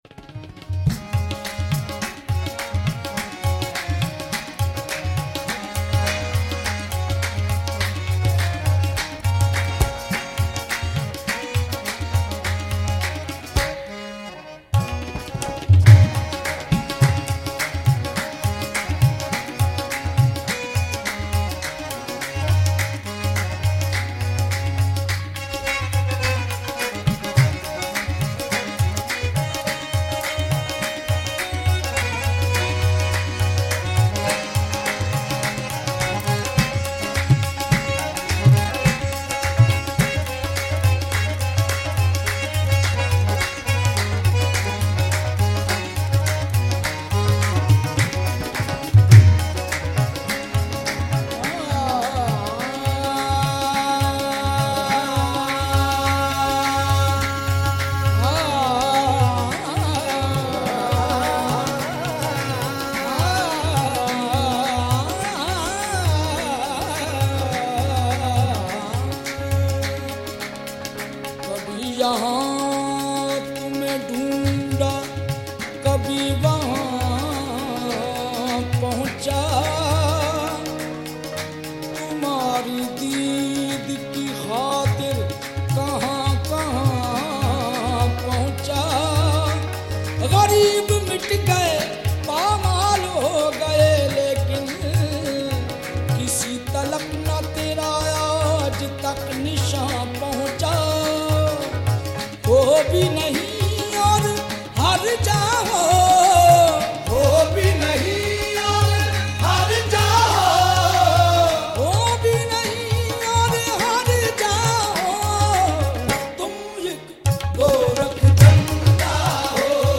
Sufi Songs